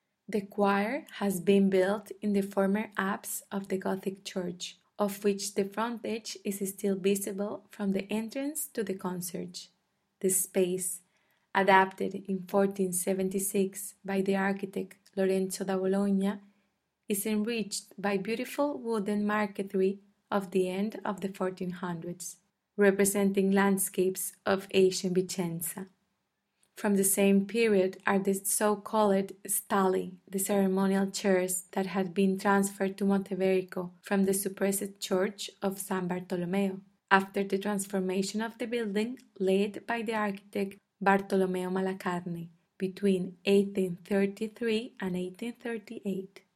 thechoir.mp3